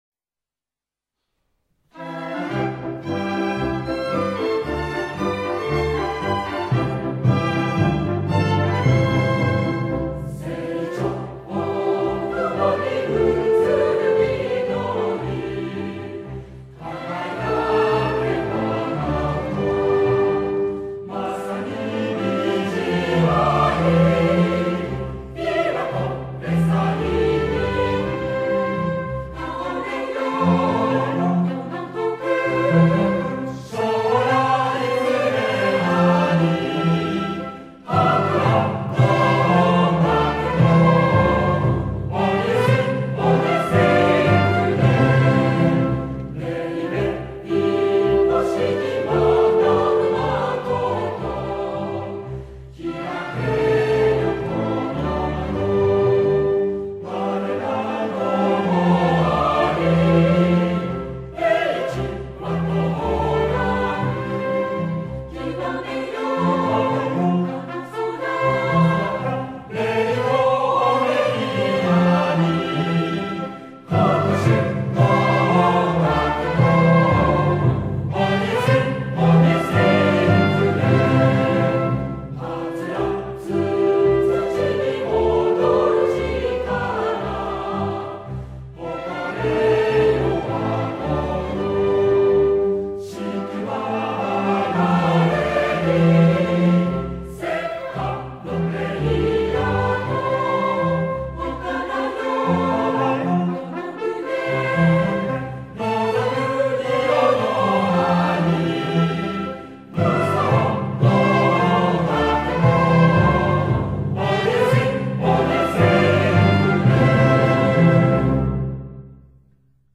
01学歌.mp3